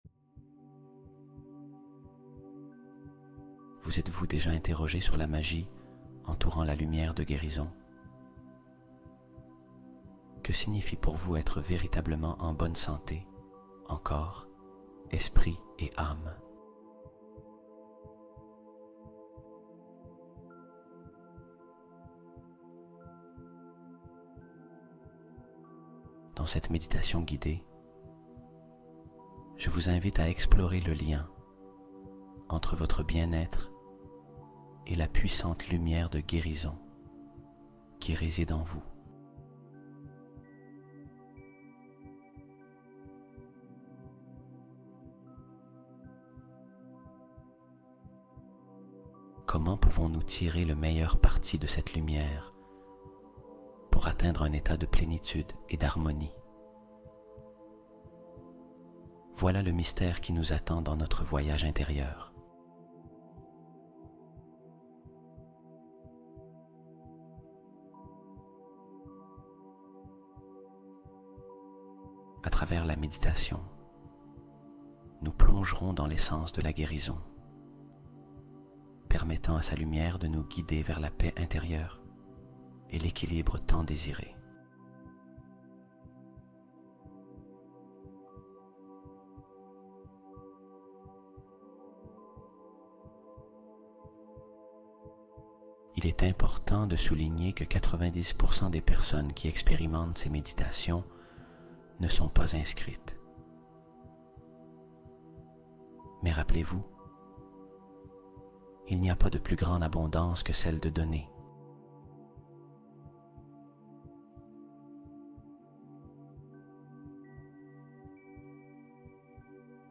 Écoute 3 nuits seulement | Hypnose qui reprogramme ton esprit invincible pendant le sommeil